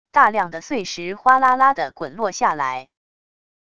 大量的碎石哗啦啦的滚落下来wav音频